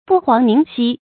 不遑宁息 bù huáng níng xī
不遑宁息发音